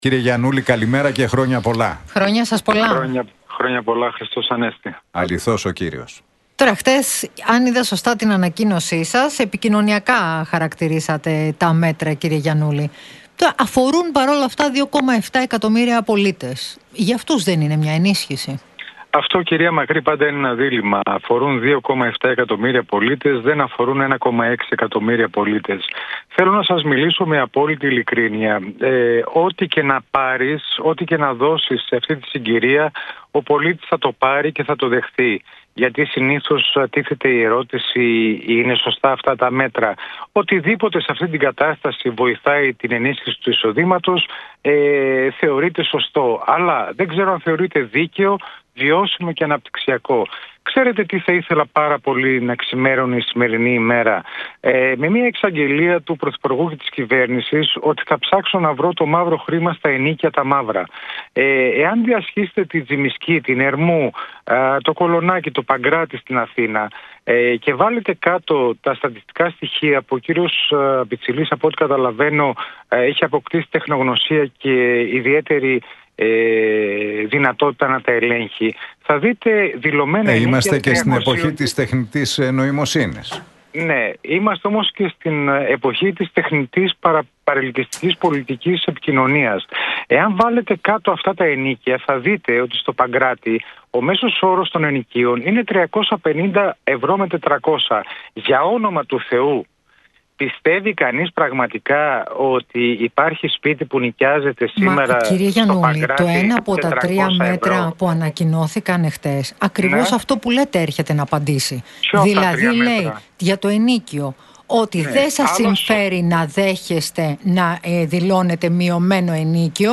μίλησε ο Χρήστος Γιαννούλης στον Realfm 97,8